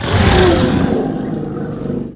c_croccata_dead.wav